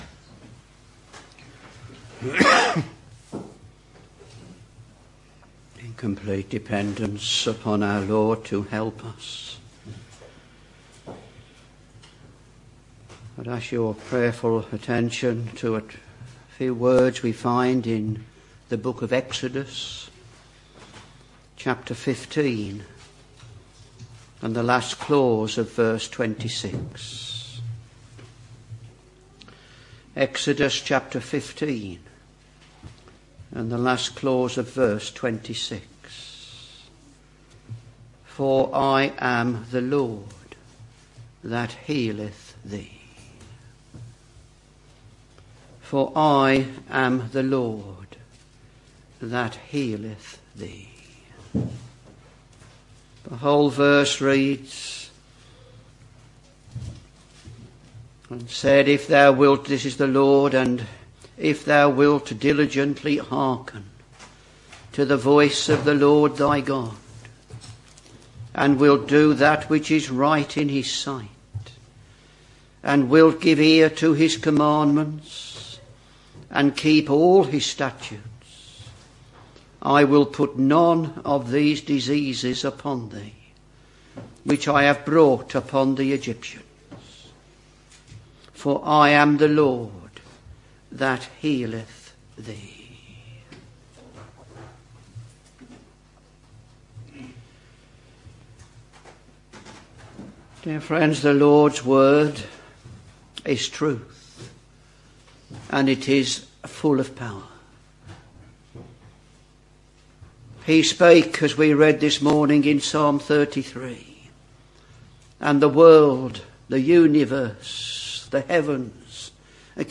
Sermons Exodus Ch.15 v.26